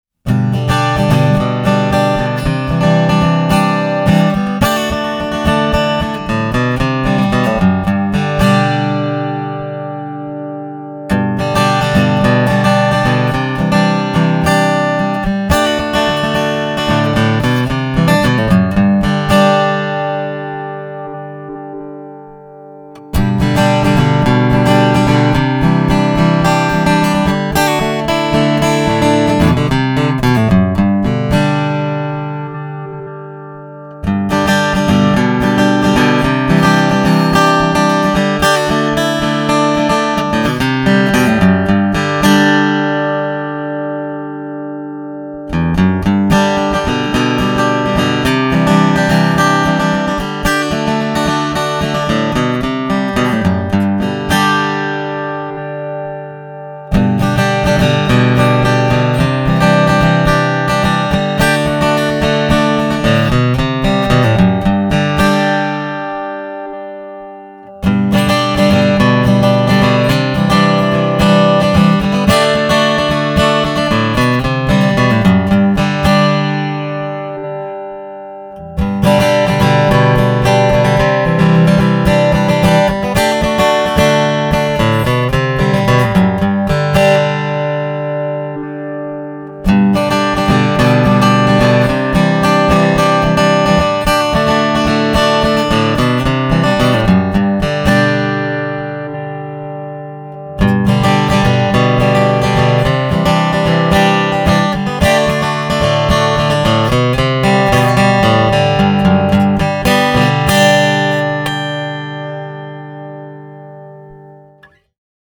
The sounds you’ll hear are recorded direct, using Image at full and no EQ.
The Aura F1 offers nine Images, which use convolution and modelling technology to transform the relatively bland tone of a piezo transducer into the lush timbre of an acoustic guitar recorded with a quality microphone.